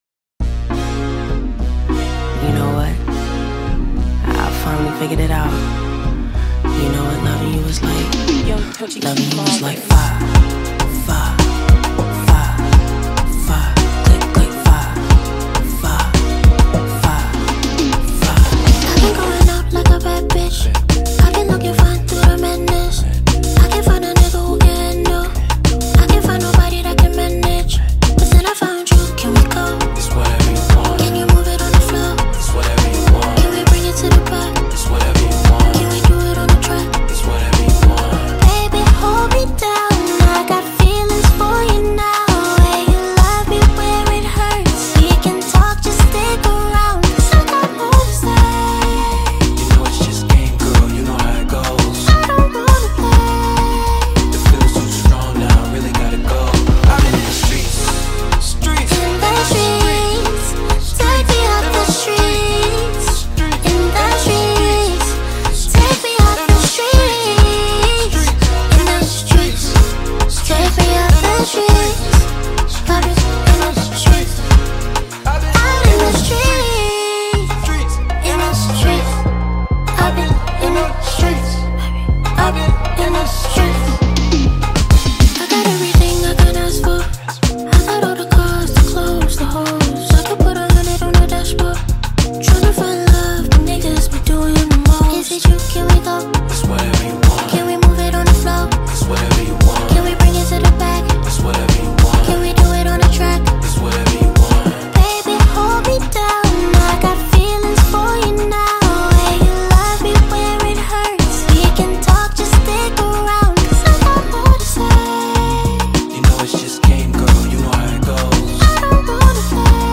Ghanaian female singer and songwriter